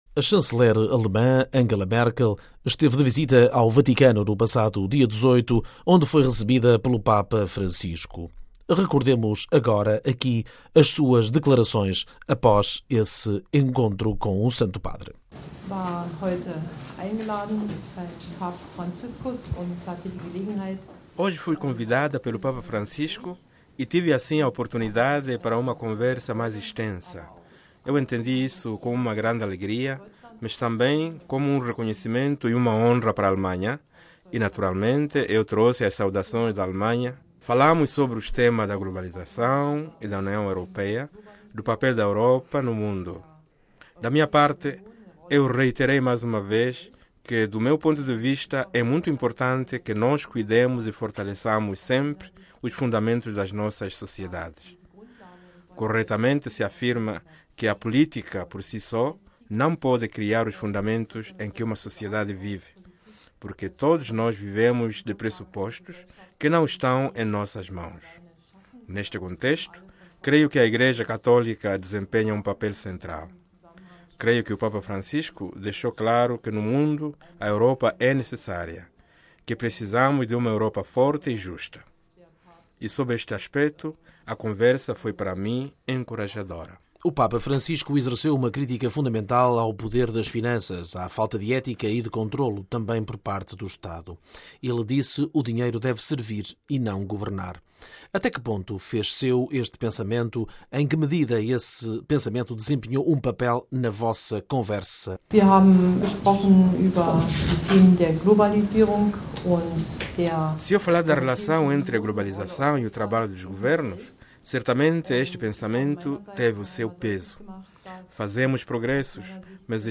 A Chanceler alemã Angela Merkel esteve de visita ao Vaticano no passado dia 18 onde foi recebida pelo Papa Francisco. Recordemos aqui e agora as suas declarações proferidas na altura….